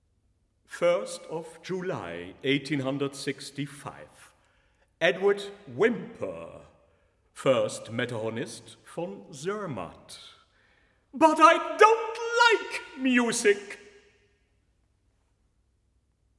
Albert Moeschinger: Edward Whymper, 1865 (Tenor, piano)